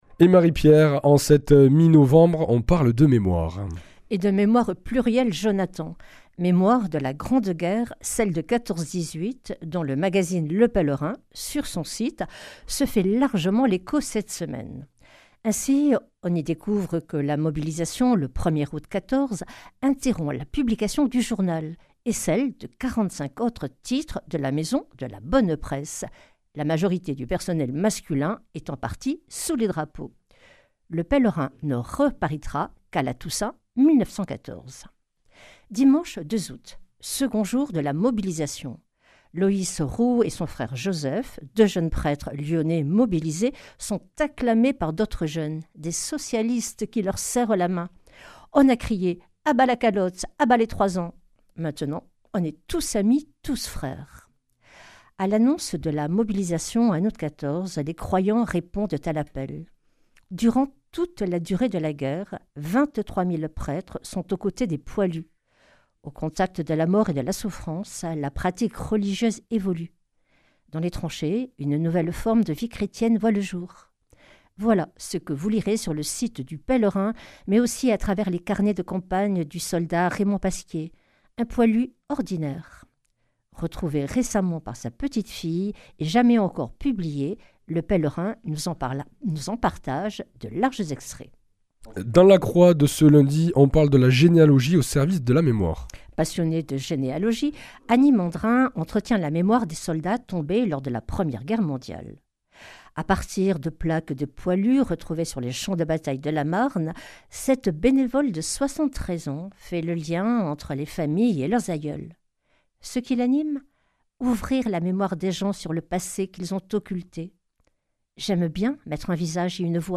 Revue de presse
Journaliste